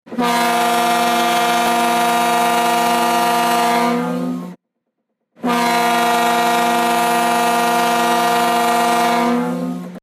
Download this ringtone featuring the horn of the R/V Neil Armstrong, the newest member of the Woods Hole Oceanographic Institution fleet.
Horn.mp3